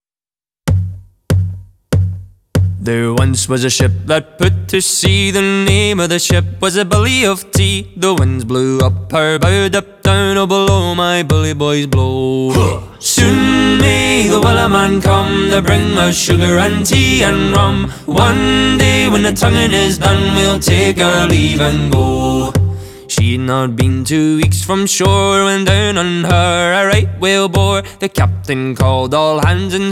• Vocal